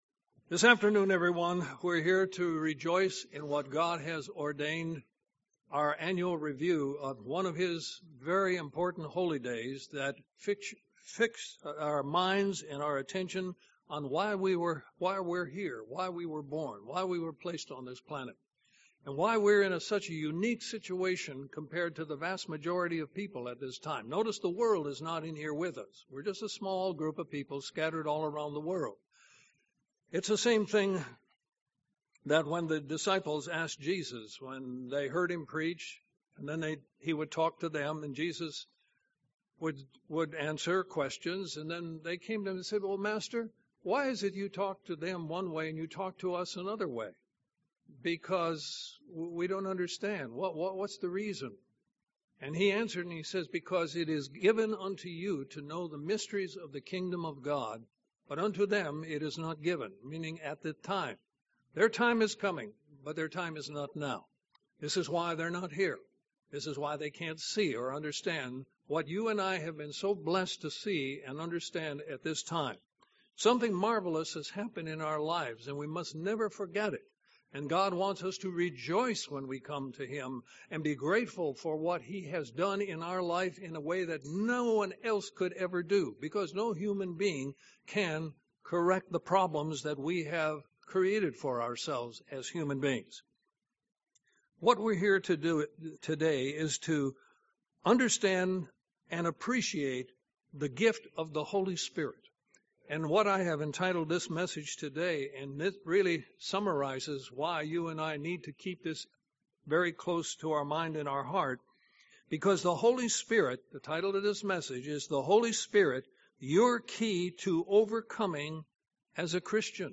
Sermons
Given in Columbus, GA